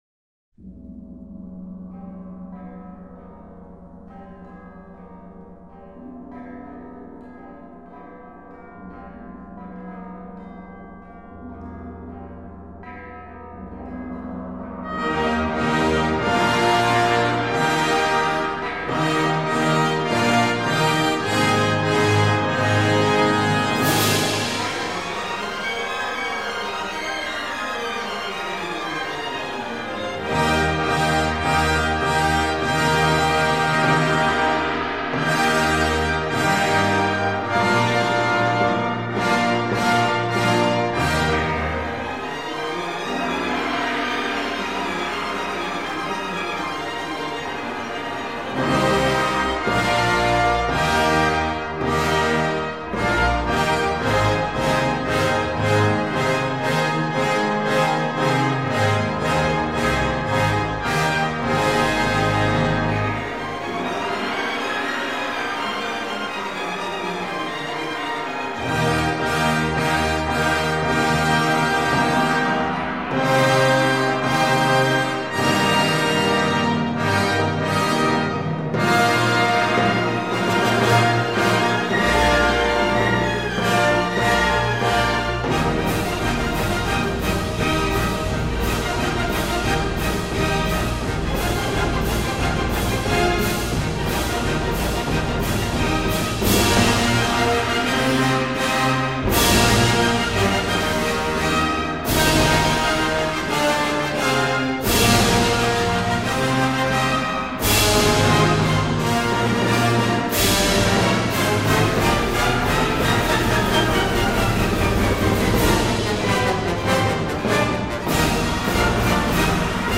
файл) 2,61 Мб Увертюра «1812 год» П.И. Чайковского (финал) 1